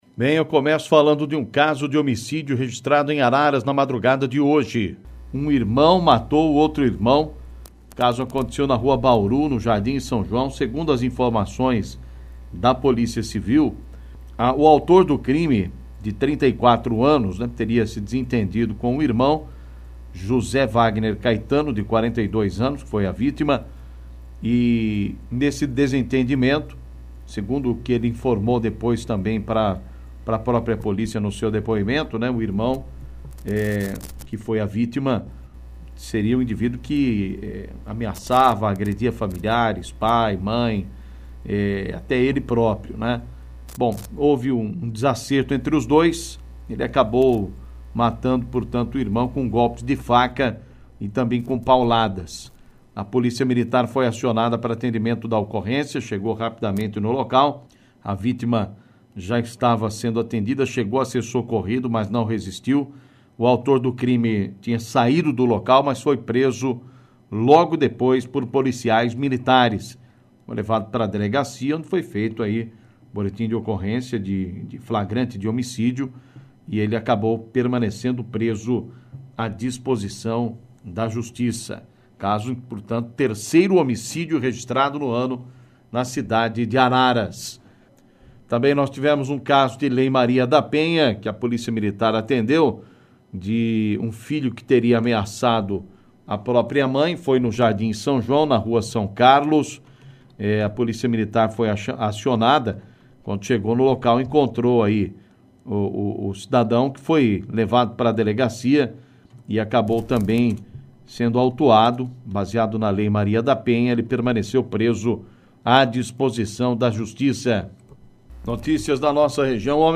Destaque Polícia